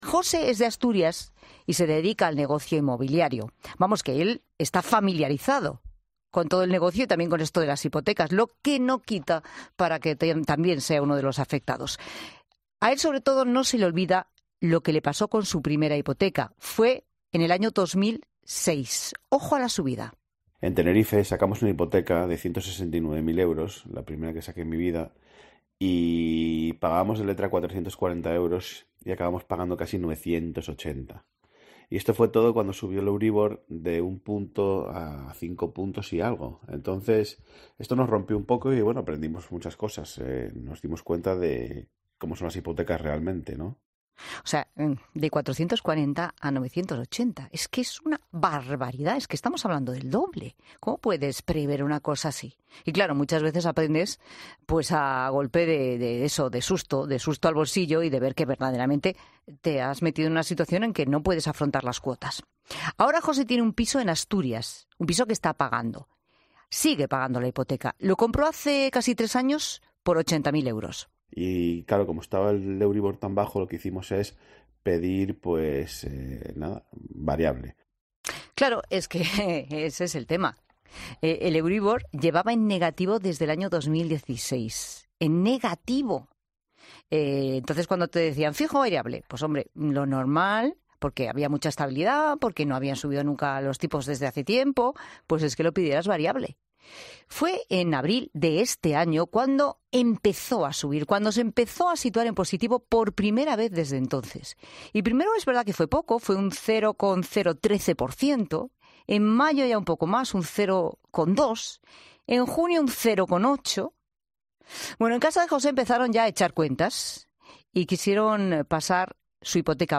Ahorrar en la Hipoteca Inmobiliaria en Málaga 2023: Entrevista de Radio, Cadena Cope. Programa La Tarde de Pilar Cisneros y Fernando de Haro.